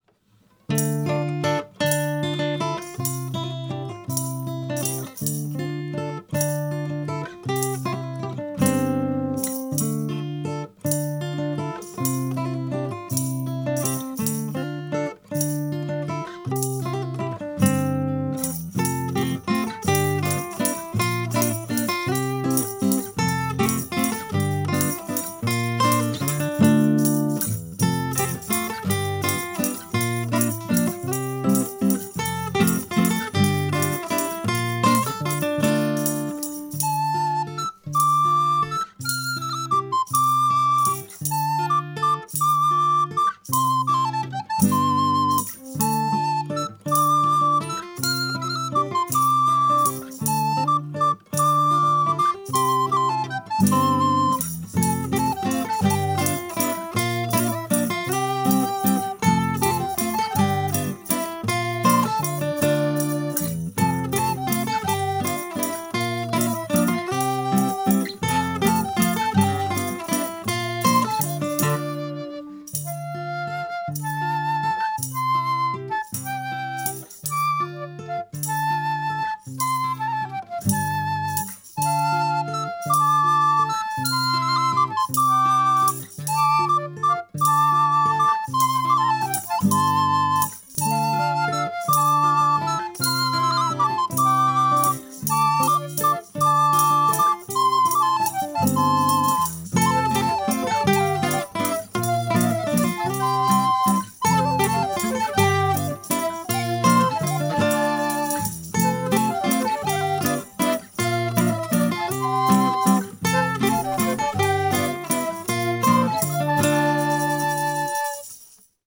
background music